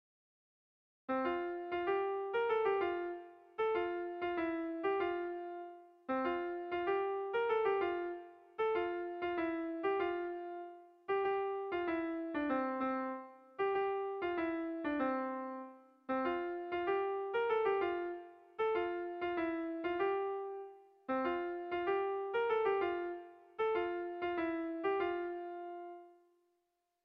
Hamarreko txikia (hg) / Bost puntuko txikia (ip)
AABAA